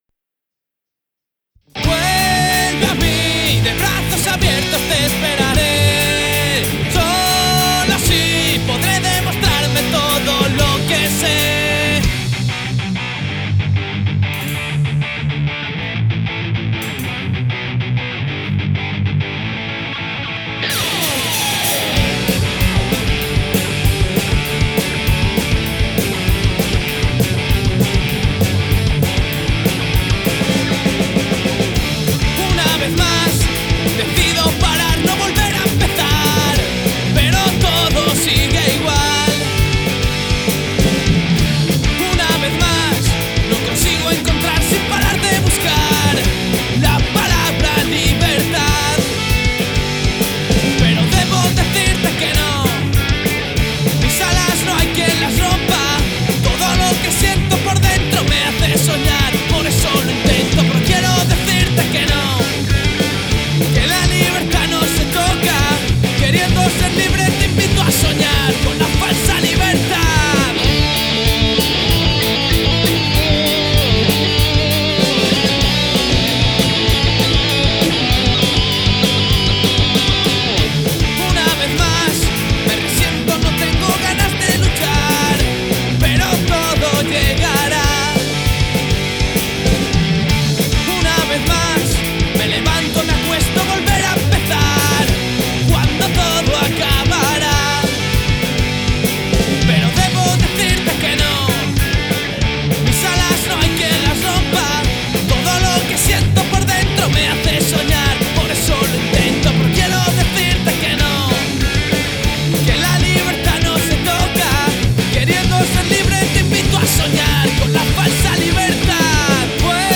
Voz
Guitarra
Guitarra y segunda voz
Bajo
Batería